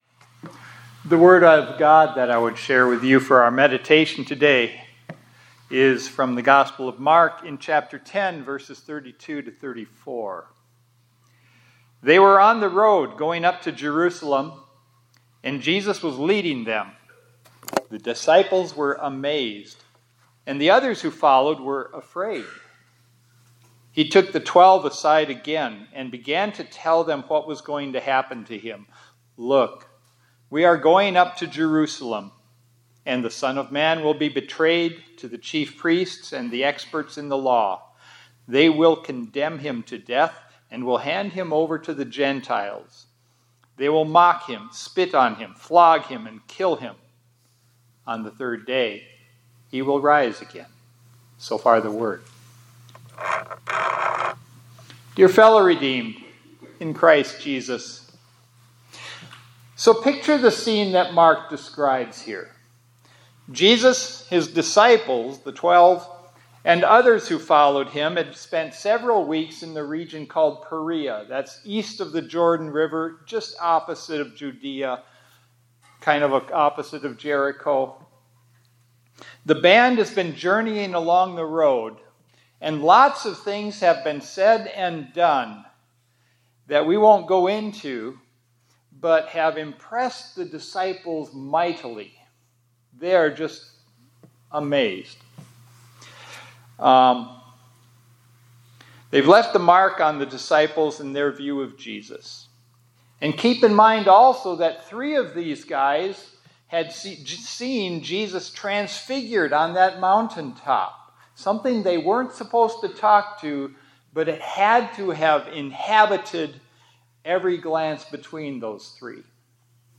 2025-03-04 ILC Chapel — Go up to Jerusalem, Go with Amazement and Fear – Immanuel Lutheran High School, College, and Seminary